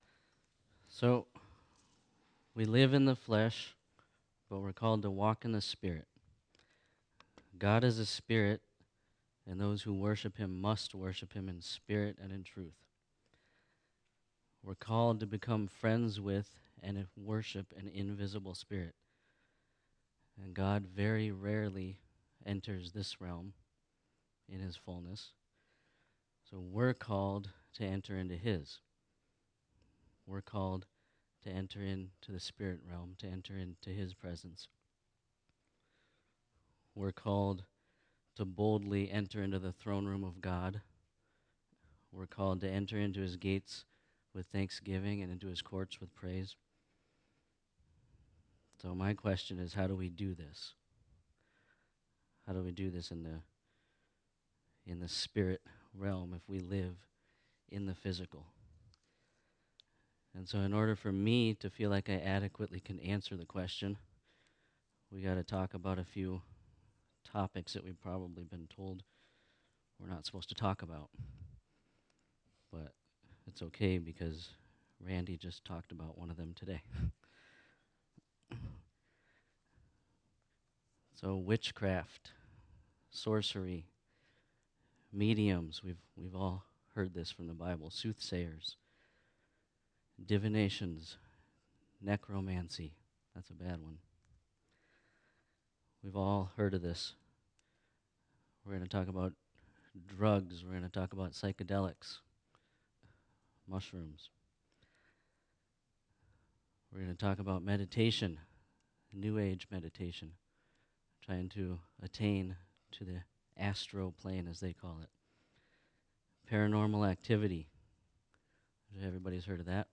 Sunday Afternoon Class: Walking in the Spirit – Part 1